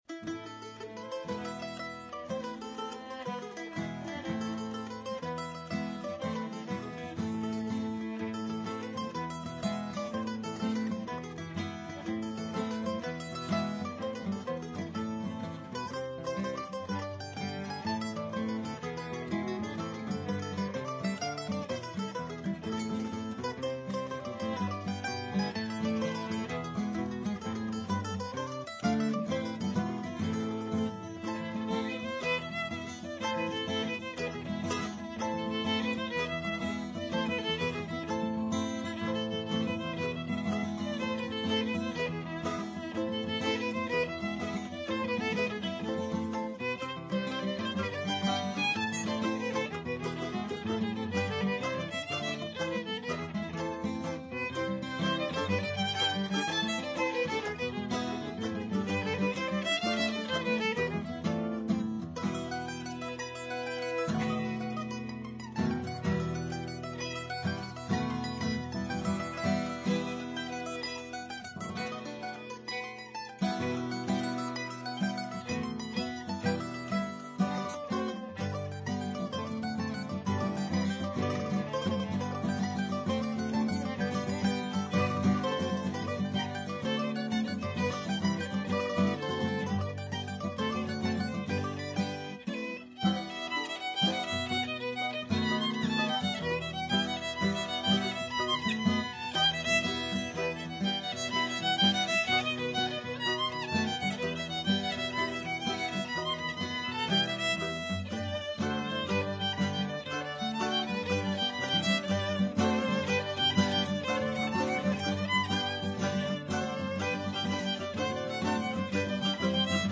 This is the bluegrass band I've been playing with for the past ... many years.
mandolin
banjo
fiddle
guitar
We recently added some British Isles music, i.e. non-bluegrass. Here are a few medleys: Broken Sixpence The Dipper The Teetotaller Return
05 The Dipper 3 (Medley).mp3